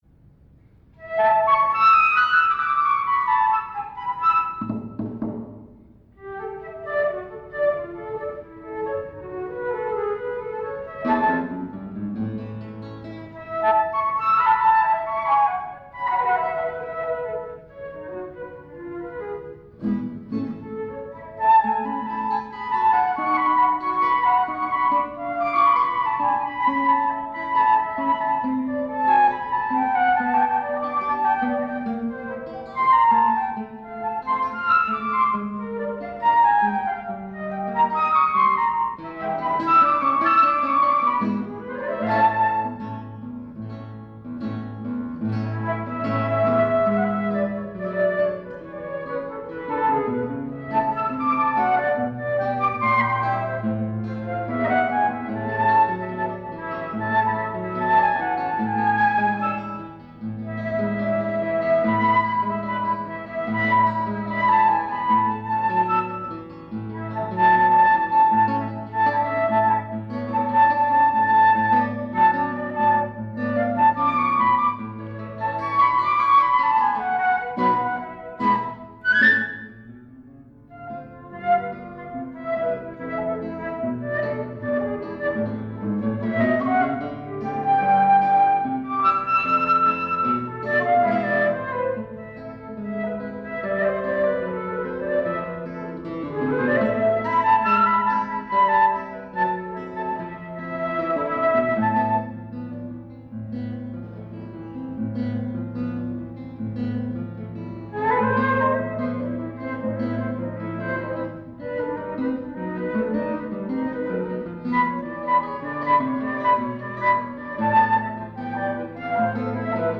Duo flauto e chitarra
Circolo Eridano, Torino 2 Aprile 1993